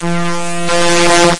描述：播放大胆后发生了一些声音
声道立体声